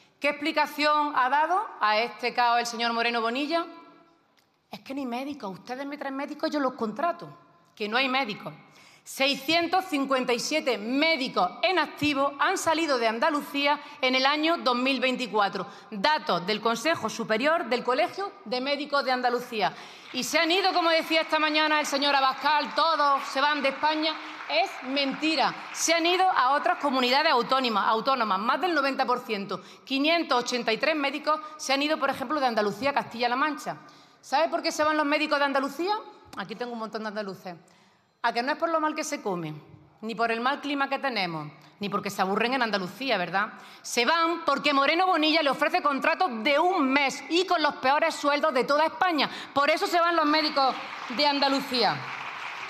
En una intervención en el Congreso sobre sanidad pública, Cobo acusó al PP de hundir la sanidad pública andaluza, “con 2 millones de pacientes en listas de espera, 1 millón de andaluces esperando para conocer un diagnóstico, 500.000 niños y niñas sin pediatra y entre 11 y 15 días de espera media para ser atendidos en Atención Primaria”.